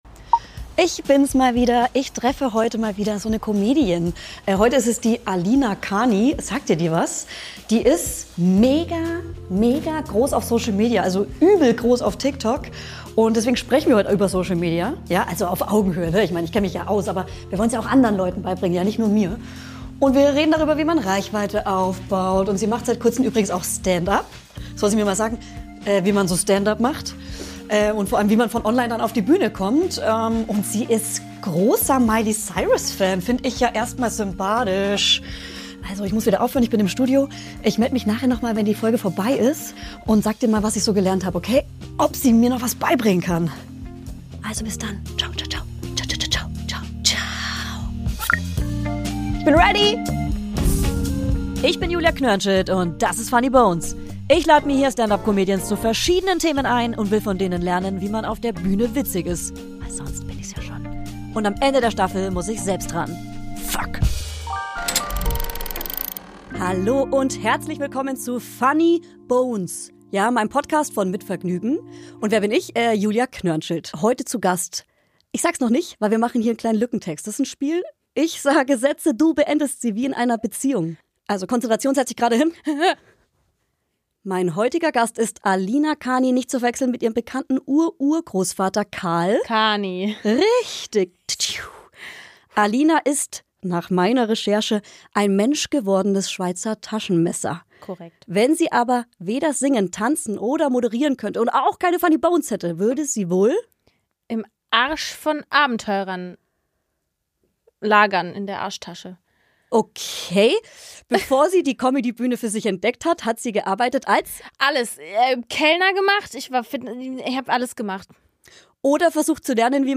Funny Bones ist ein Podcast von Mit Vergnügen.